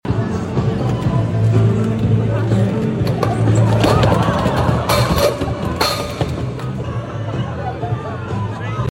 🤖Robot Goes Berserk After Falling? sound effects free download